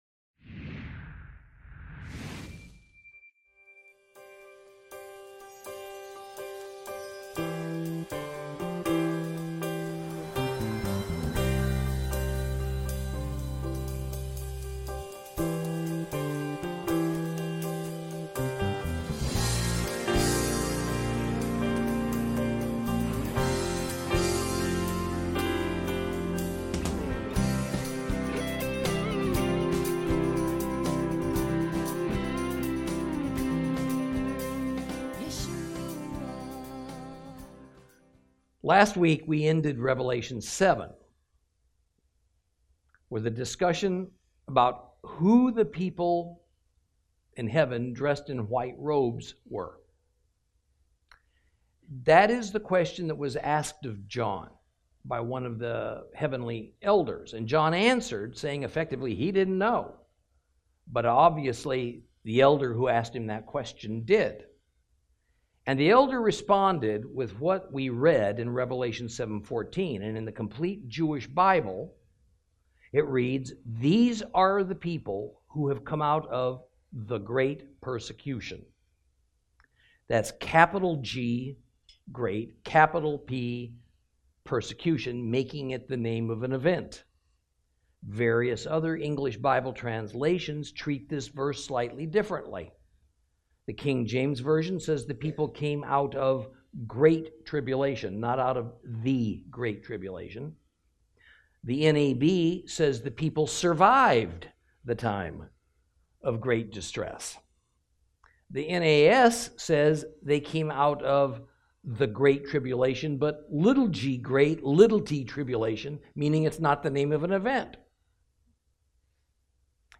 Lesson 18 Ch7 Ch8 - Torah Class